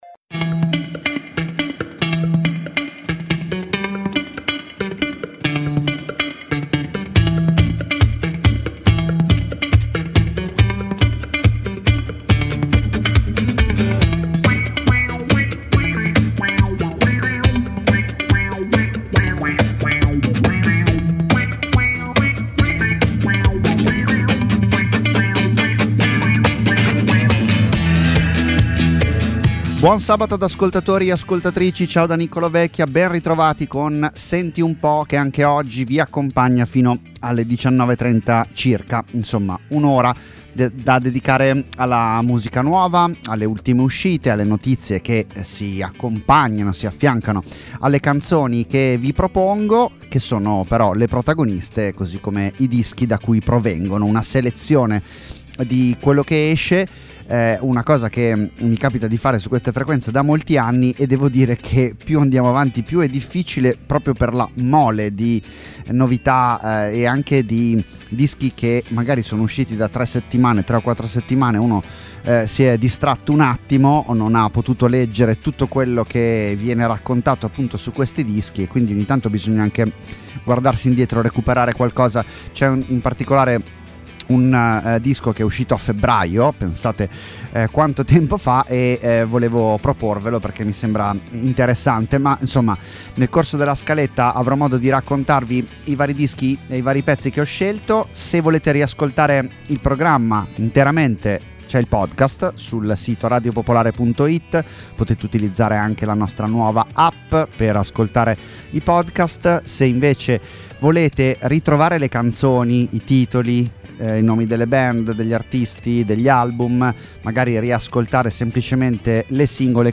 Ospiti, interviste, minilive, ma anche tanta tanta musica nuova. 50 minuti (circa…) con cui orientarsi tra le ultime uscite italiane e internazionali.